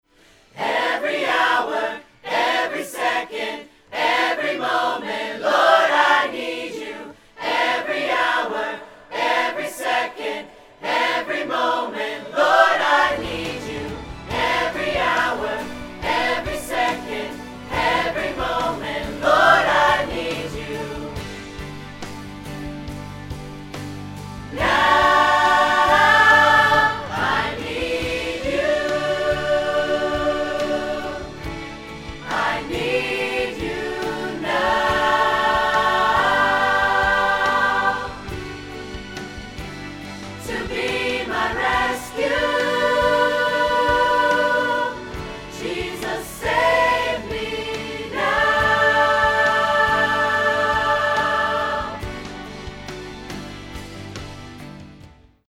• 0:00 – 0:06 – Choir Mics Soloed
• 0:07 – 0:13 – Choir Mics with Reverb
• 0:13 – 0:55 – Choir Mics with Backing Track
RODE NT5